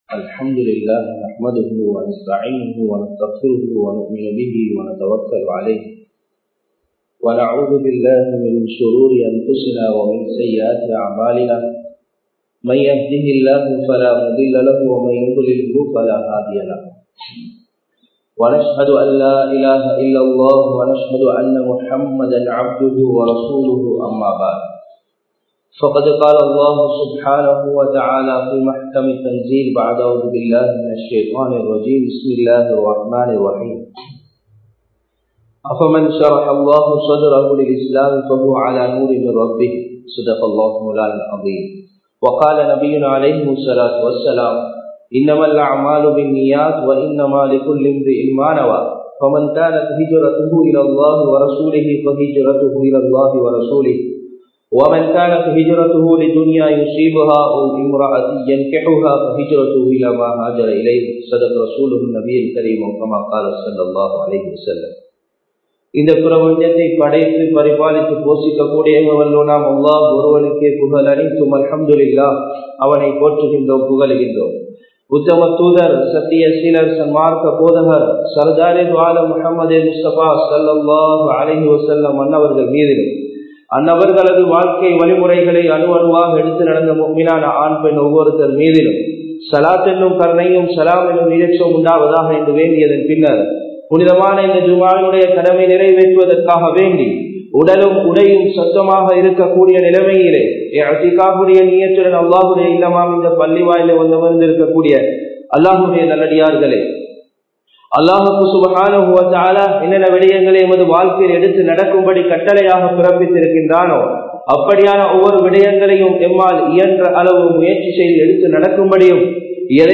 இஸ்லாமியக் கண்ணோட்டம் (An overview of Believeness) | Audio Bayans | All Ceylon Muslim Youth Community | Addalaichenai
Akurana, Dalgastana, Habeeb Jumua Masjid 2020-07-10 Tamil Download